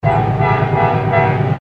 Country Horn   2819 Country